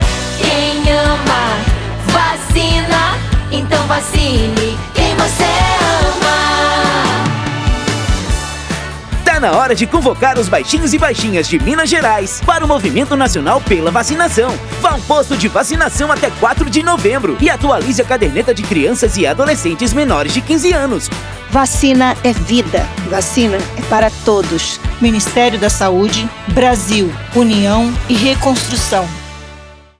Áudio - Spot 30seg - Campanha de Multivacinação em Minas Gerais - 1,1mb .mp3